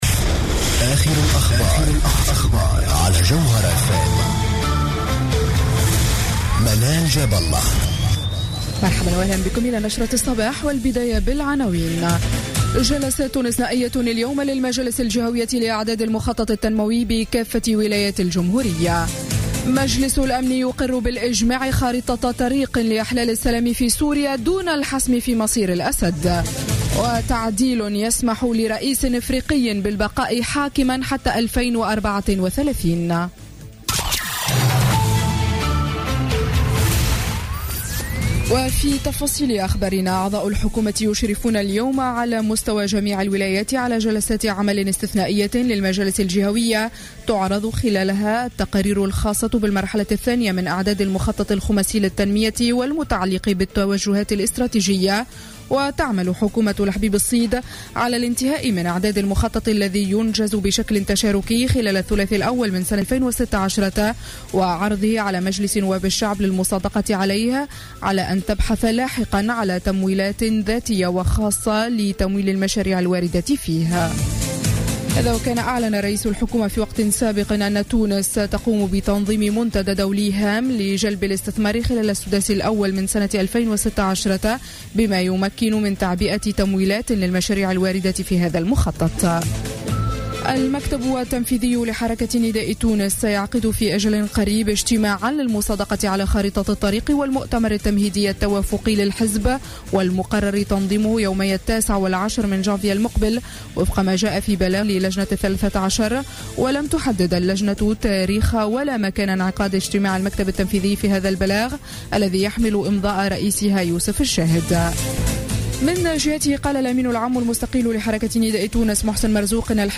نشرة أخبار السابعة صباحا ليوم السبت 19 ديسمبر 2015